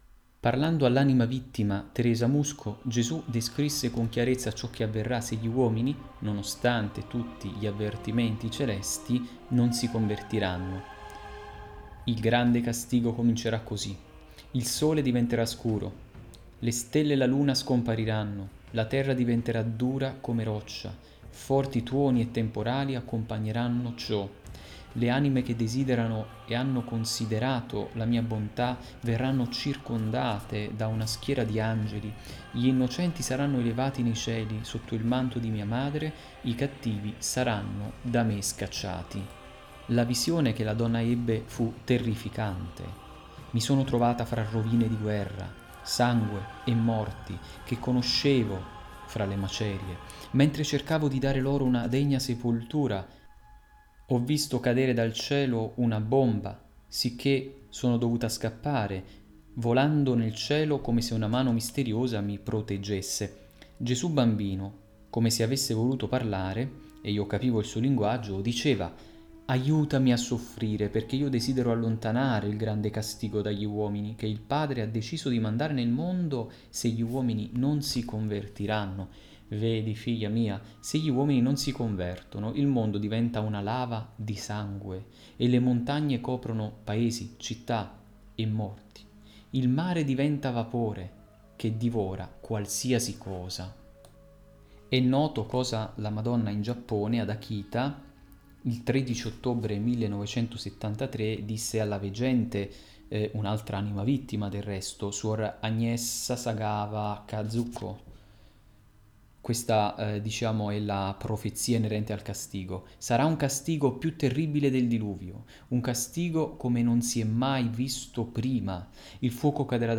Dato che il capitolo era particolarmente lungo e ben curato, in questo podcast che ho realizzato non ne ho dato lettura continua ma ho fatto una selezione di quelle che mi sembravano più significative ed utili da proporre.